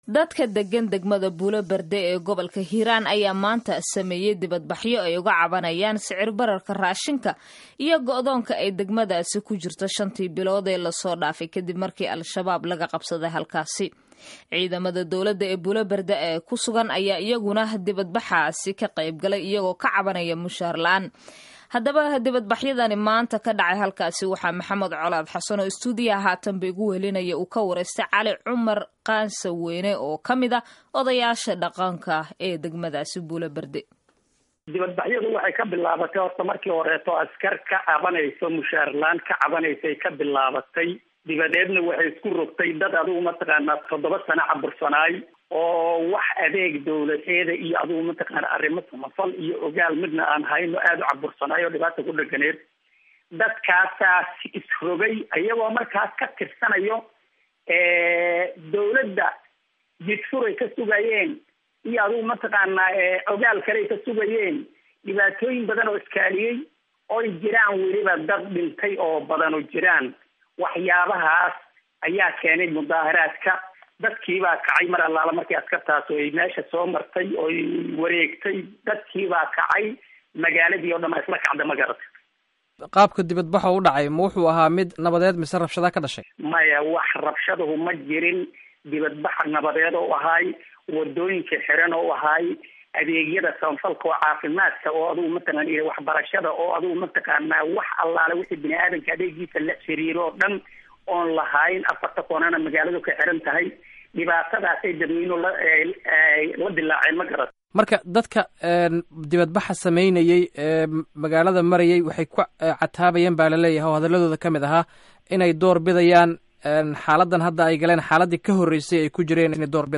Dhageyso Wareysiga Banaanbaxa Buula-Burde